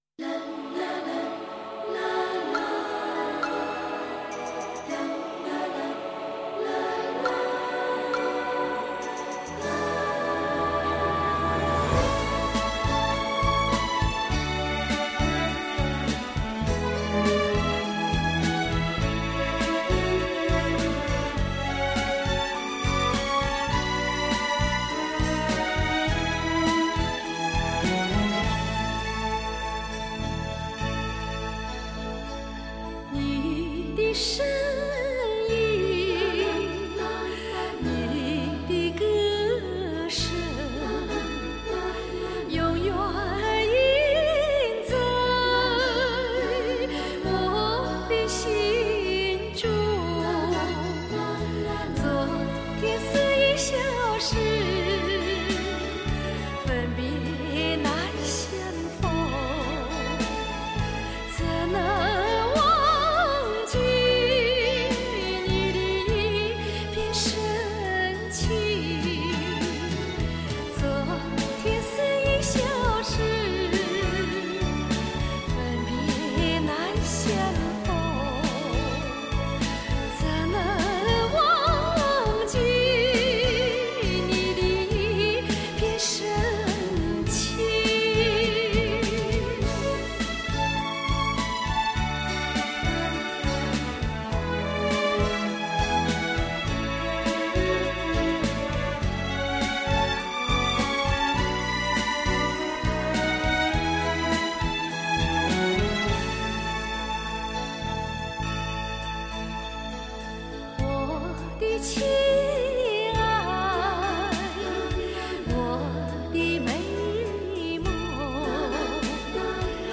质朴，自然，真实，简单，这种清纯之美就已经足够令人陶醉。